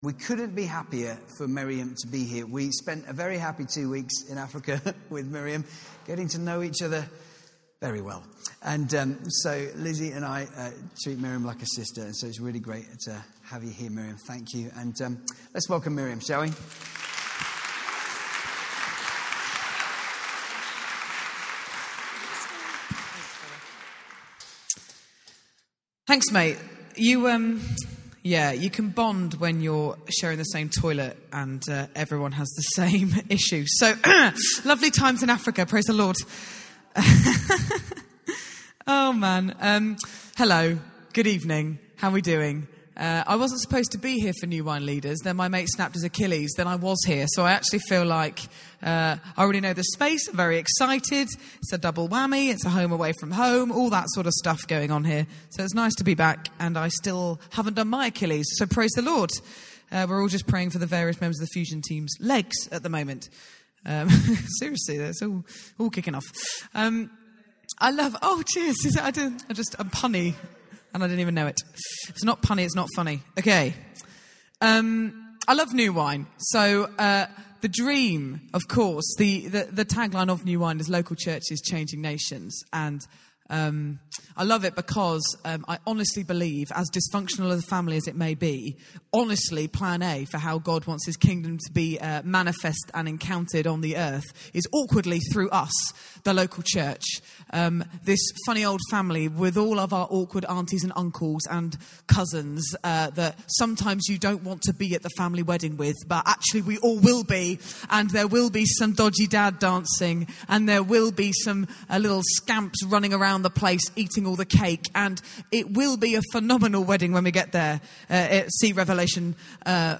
New Wine Evening Celebration - Sunday 2nd April 2017